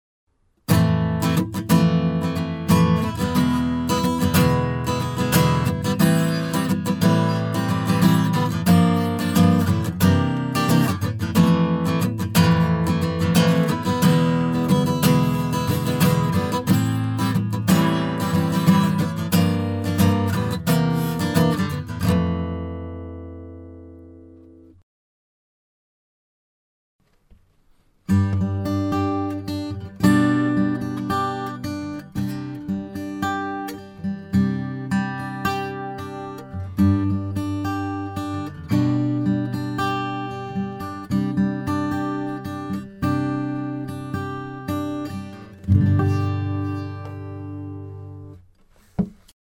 試聴用のオーディオは、前半にストローク、後半にアルペジオが入っています。
コンプレッションスタイルOPTOをかけてみました。
ピッキングの強い瞬間が抑えられた感じが出ていますね。
コンプレッションされた音がやや荒っぽい感じがしないでもない気がしますが、この辺はスレッショルドやレシオをうまく調整していくと解消されるのかもしれません。